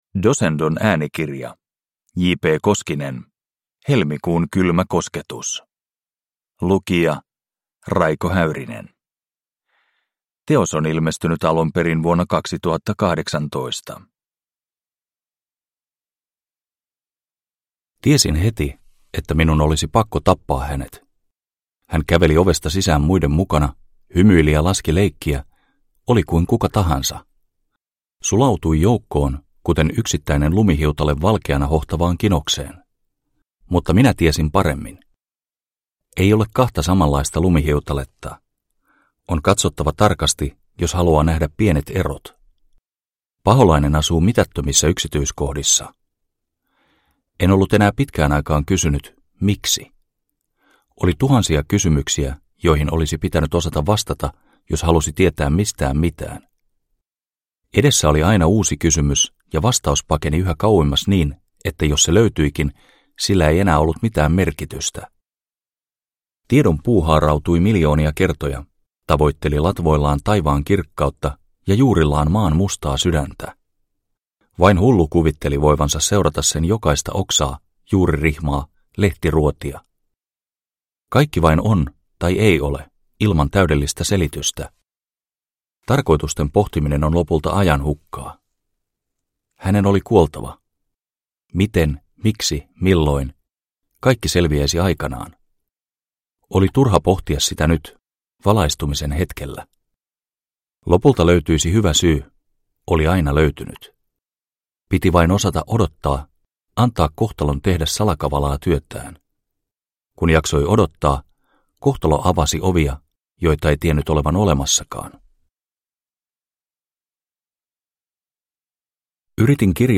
Helmikuun kylmä kosketus – Ljudbok